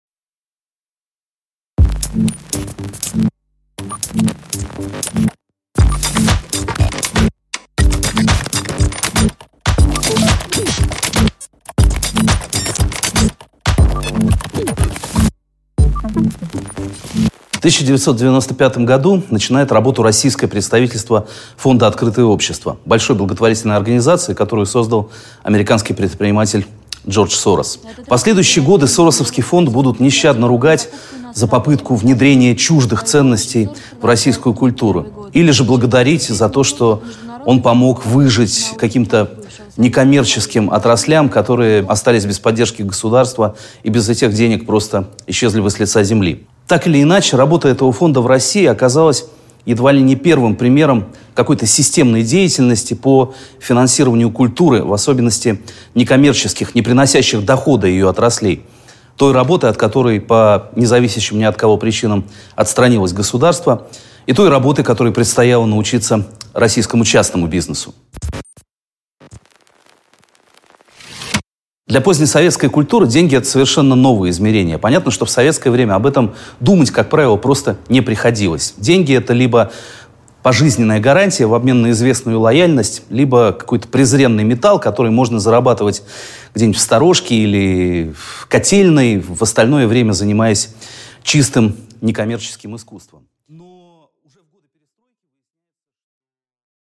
Аудиокнига Открытие денег: как зарабатывала новая культура?